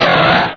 Cri de Latias dans Pokémon Rubis et Saphir.